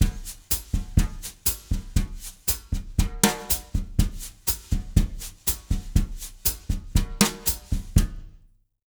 120BOSSA02-R.wav